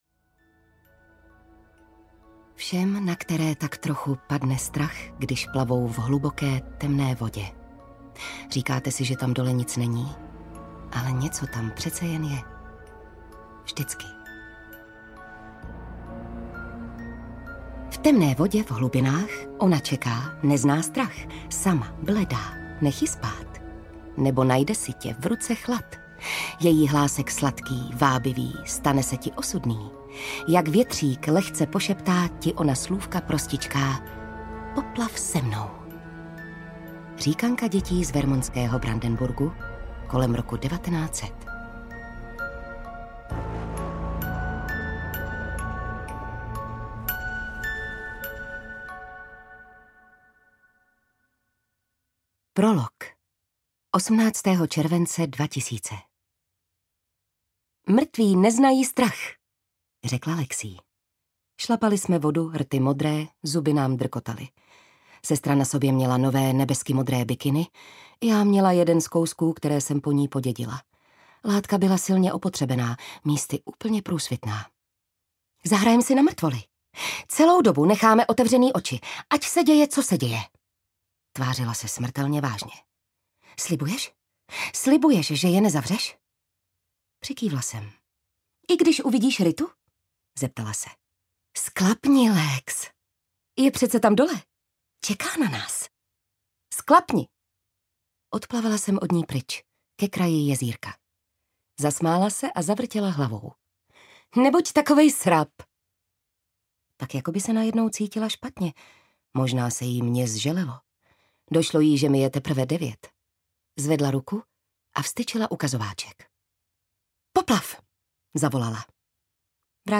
Audiokniha Tajemství pramenů, kterou napsala Jennifer McMahon. Když sociální pracovnice Jackie zjistí, že má devět zmeškaných hovorů od své starší sestry Lexie, nebere to příliš vážně.
Ukázka z knihy
• InterpretJitka Ježková